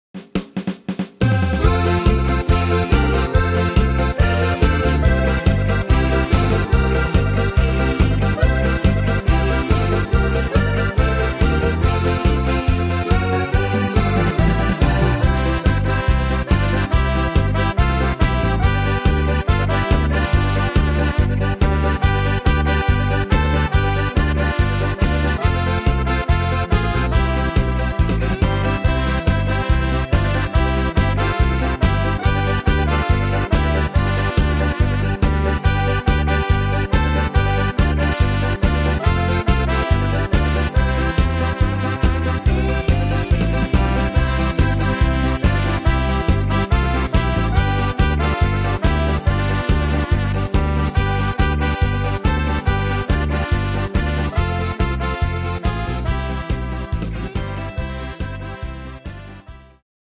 Tempo: 141 / Tonart: F-Dur